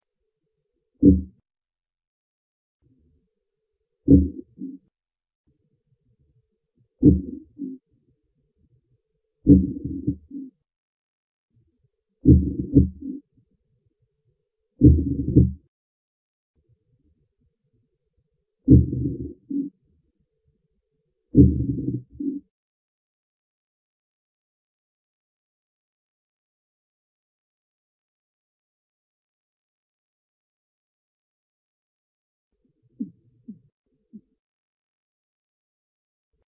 courtship 6
Сигналы ухаживания (= прекопуляционные сигналы, courtship) являются сложнопостроенными фразами, в процессе эмиссии которых самец использует сразу несколько ударно-вибрационных способов возбуждения колебаний.
Третья часть чаще всего состоит из серий, образованных УБ, ВБ и ТР.
К концу фразы интенсивность УБ, ВБ и ТР возрастает.